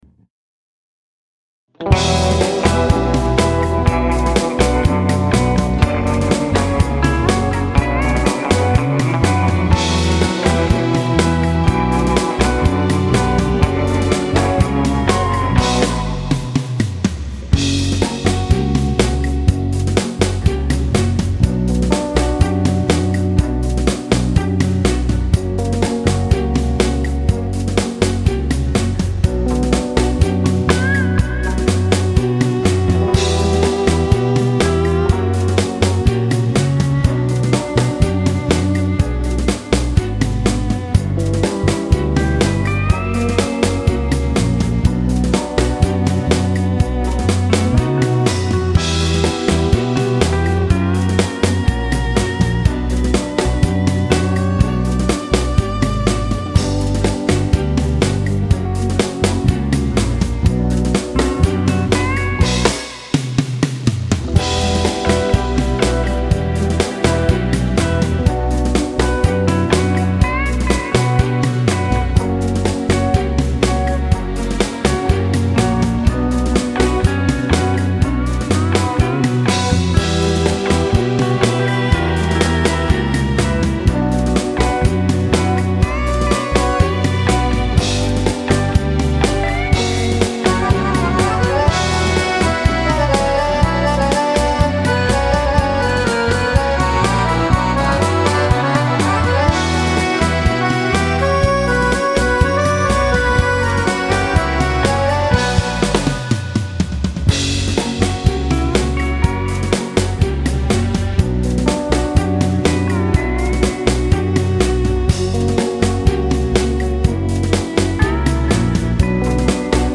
Genere: Beguine
Scarica la Base Mp3 (3,65 MB)